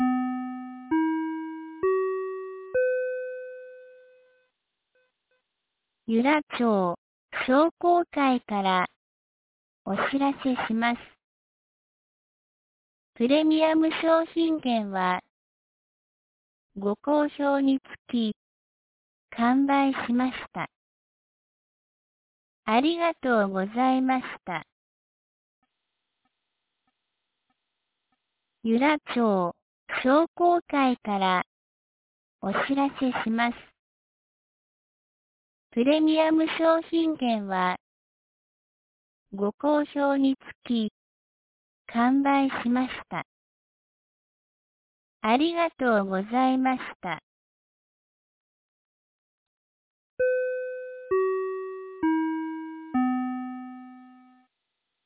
2025年11月18日 15時41分に、由良町から全地区へ放送がありました。